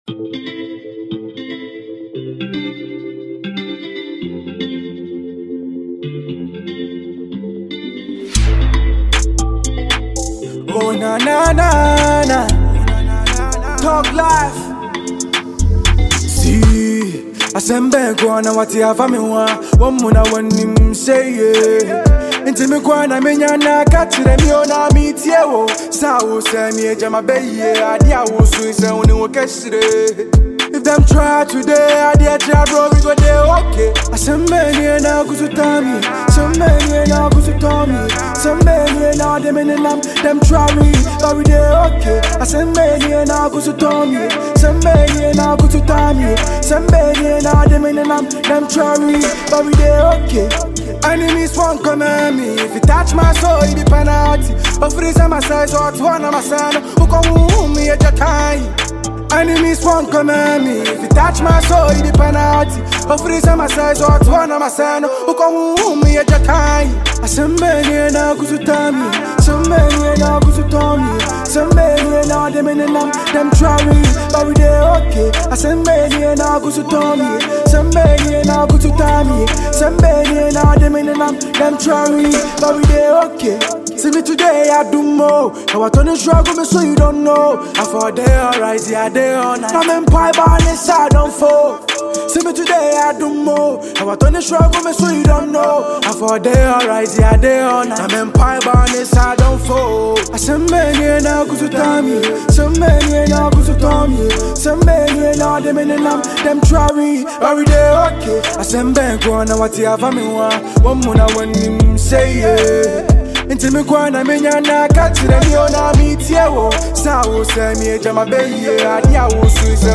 Ghana Music
an energertic song to the Ghana music scene.